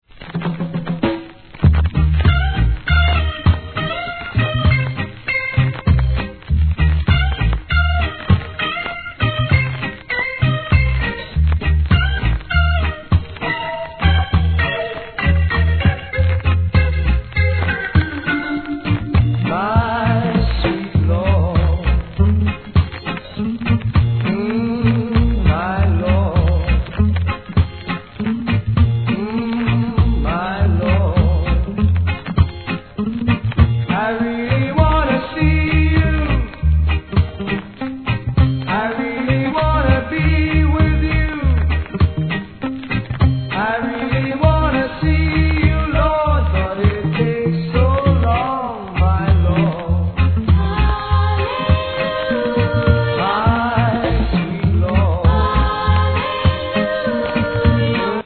頭に周期的なノイズ
REGGAE